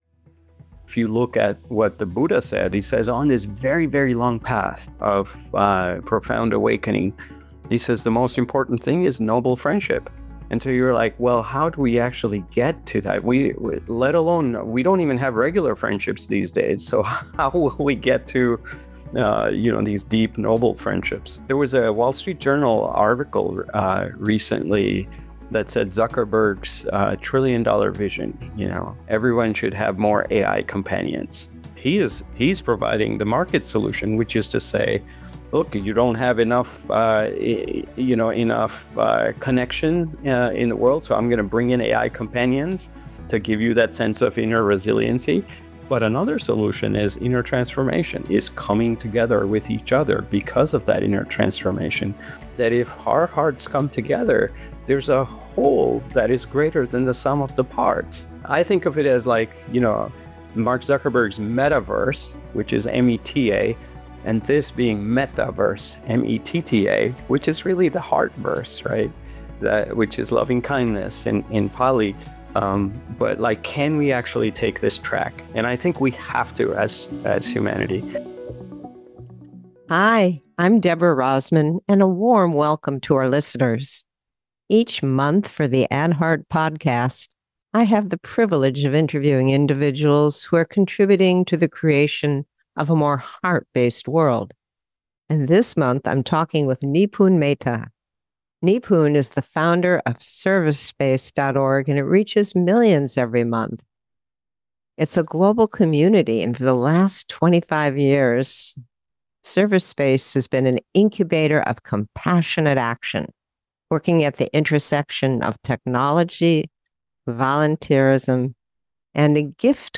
In this timely and thought-provoking conversation